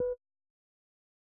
Sound / Effects / UI / Retro12.wav